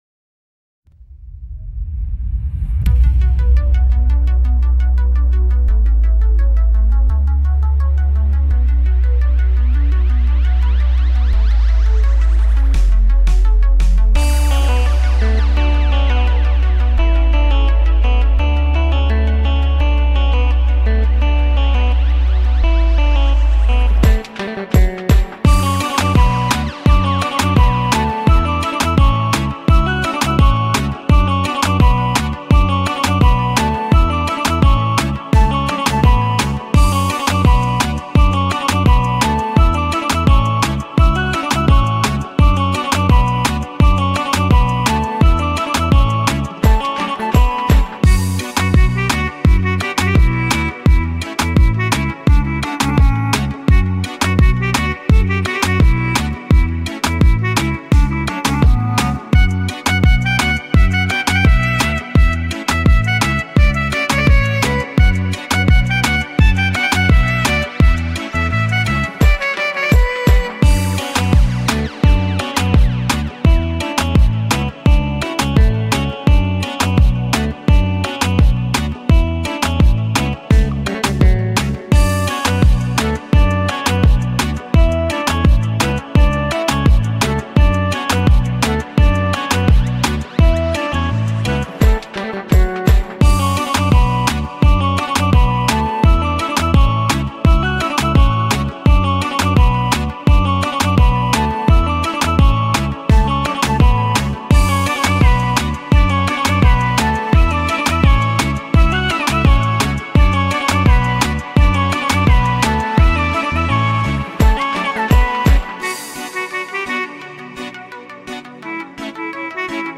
Oriental music Instrumental